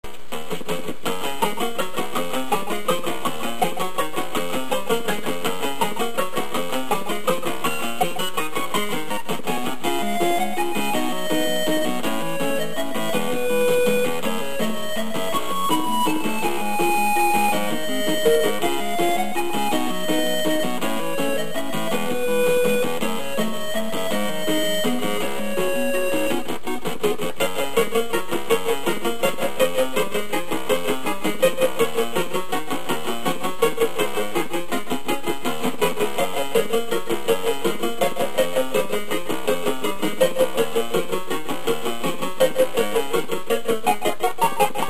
They are now in mono sound at 32khz, 64kbps in .mp3 format.